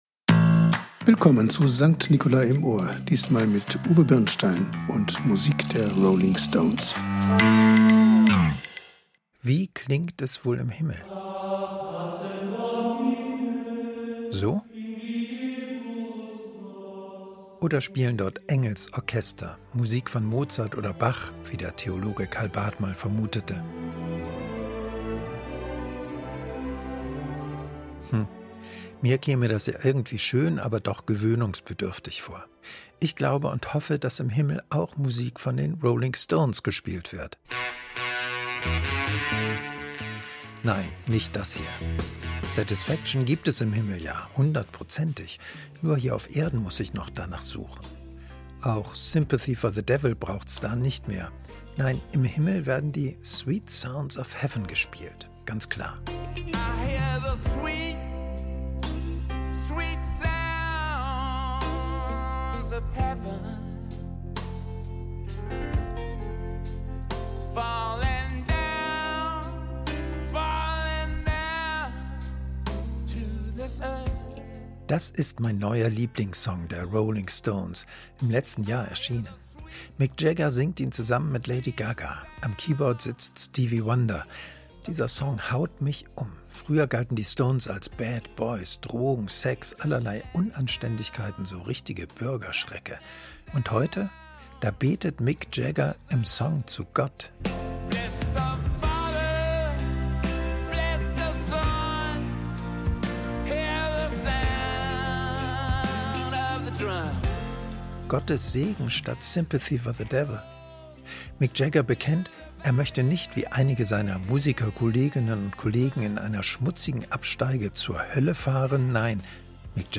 Mit Autoren, Musikern und Sprechern aus der Evangelisch-lutherischen Kirche St. Nicolai in Lemgo.